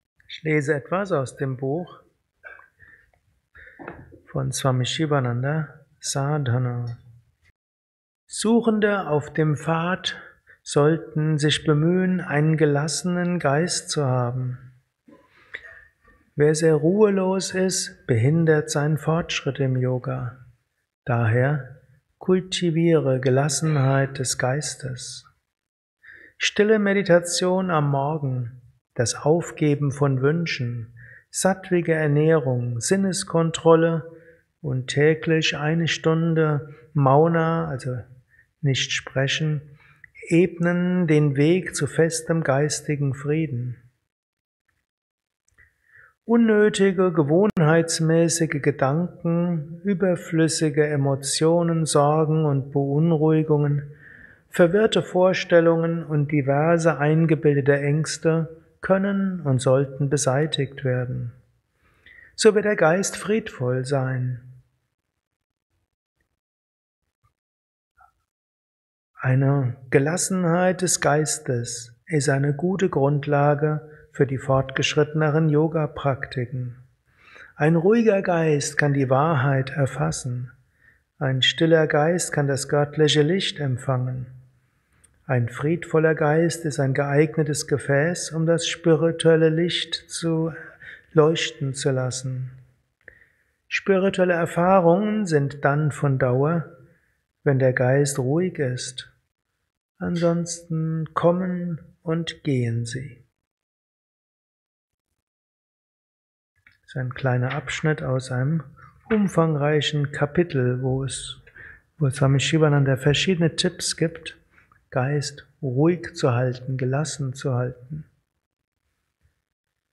Kurzvorträge
Ashram Bad Meinberg.